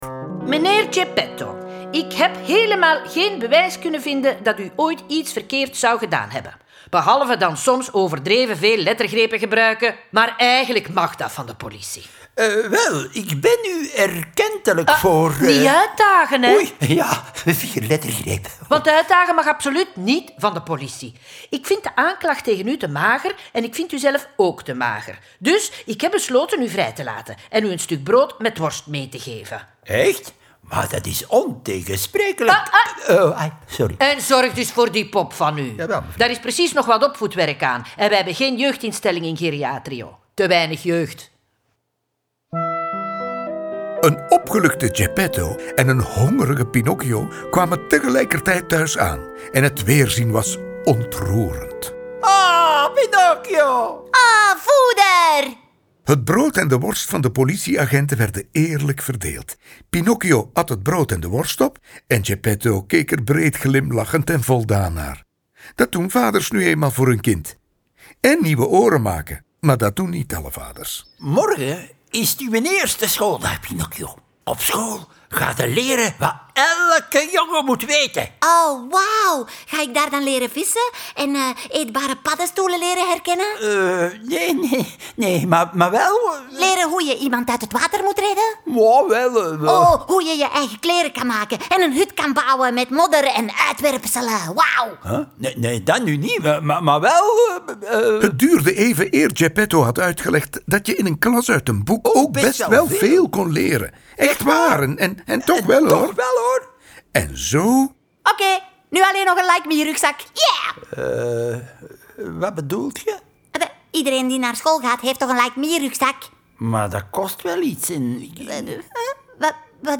Met de stemmen van Warre Borgmans, Tine Embrechts, Gène Bervoets, Sien Eggers, Tom Van Dyck, Lynn Van Royen, Alex Agnew, Bob De Moor, Els Dottermans en Koen De Graeve.
Ze worden verteld, gespeeld en gezongen door Vlaanderens meest getalenteerde acteurs en zitten boordevol humor, liedjes en knotsgekke geluidseffecten.